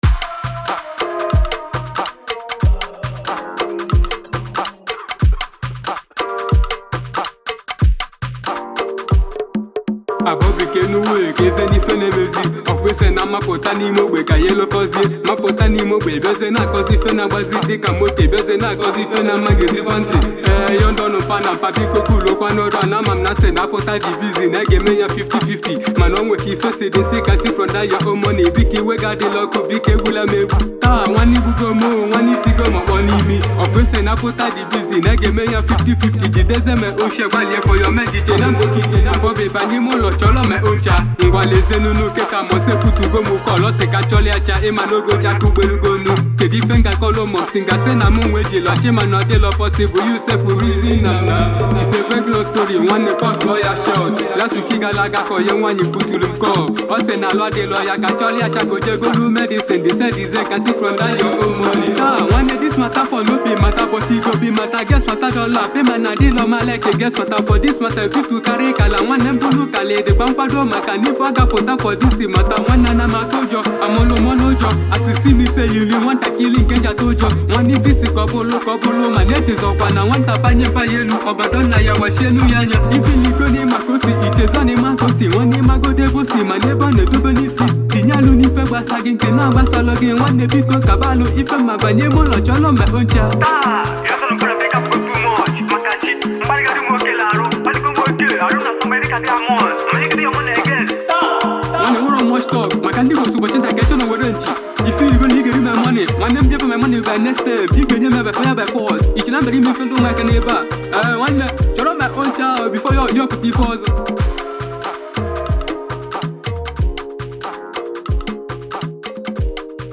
has finally drop that waiting delicious freestyle